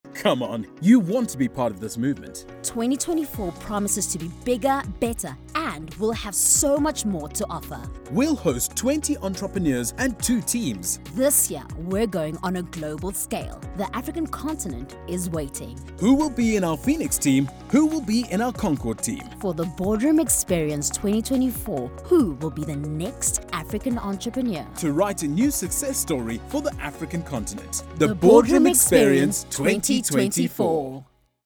Professional, broadcast-ready voice-overs delivered in both English and French.
Dialogue / Duet